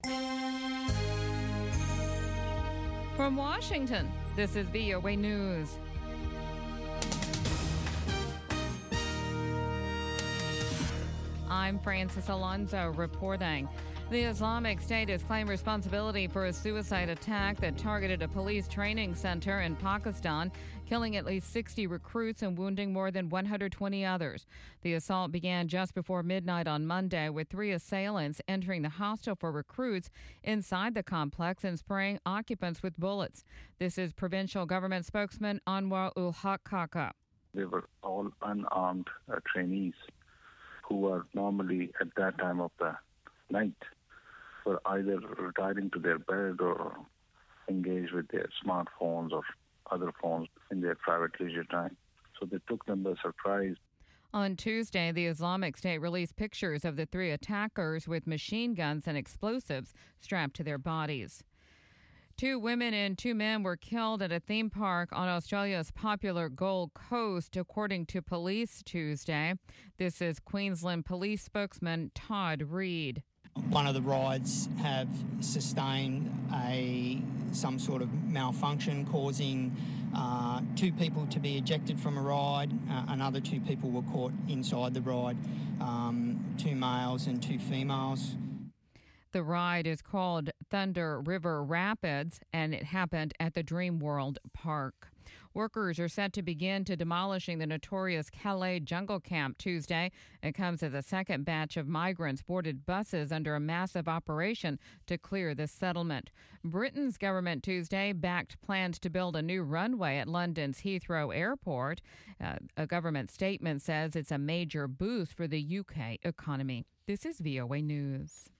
1200 UTC Newscast for October 25, 2016